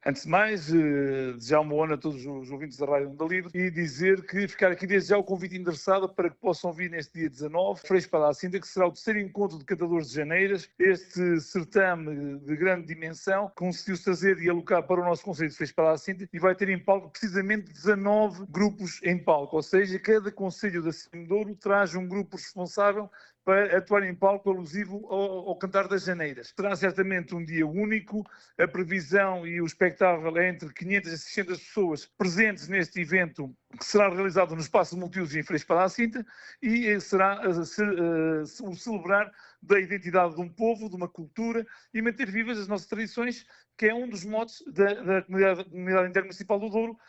Nuno Ferreira, o anfitrião e presidente da câmara Municipal de Freixo de Espada à Cinta enaltece que este será um dia único que vai manter vivas as tradições: